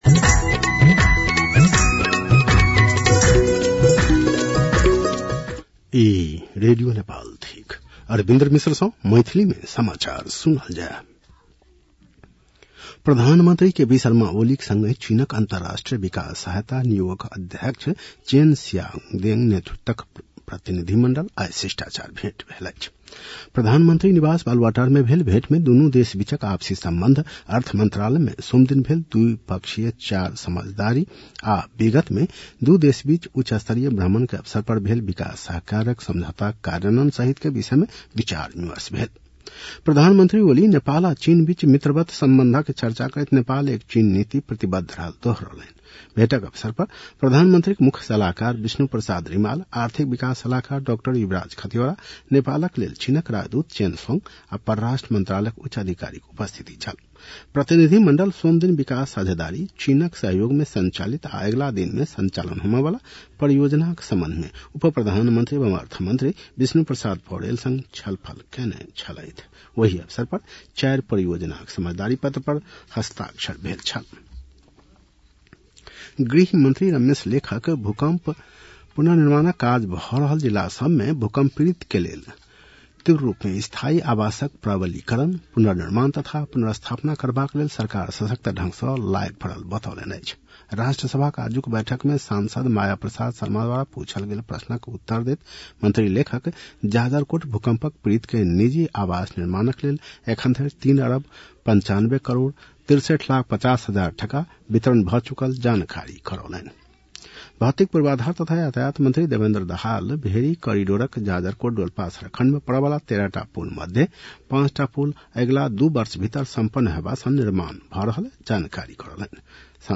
An online outlet of Nepal's national radio broadcaster
मैथिली भाषामा समाचार : ६ साउन , २०८२